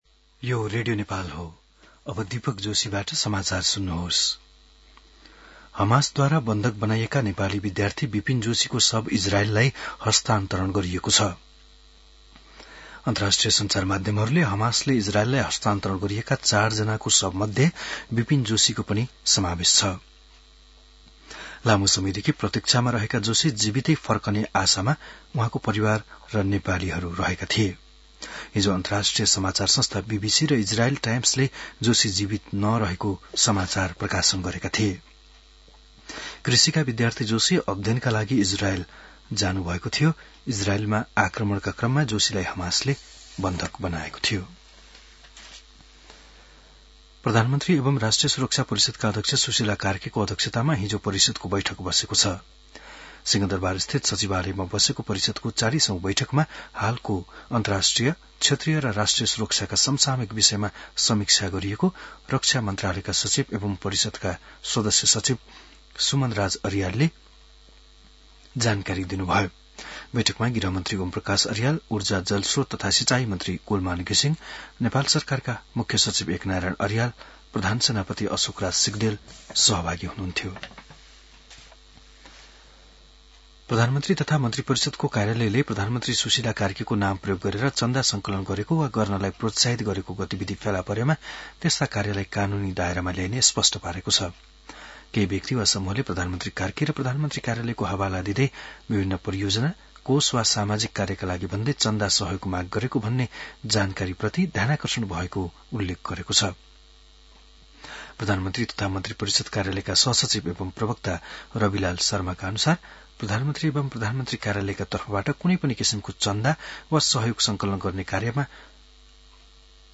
बिहान १० बजेको नेपाली समाचार : २८ असोज , २०८२